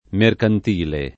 [ merkant & le ]